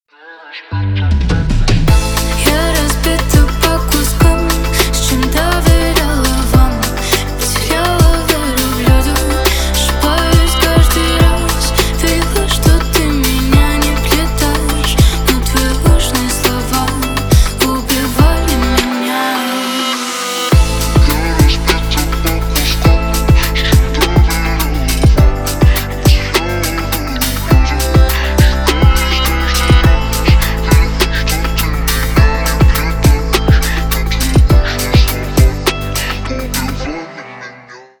Поп Музыка
грустные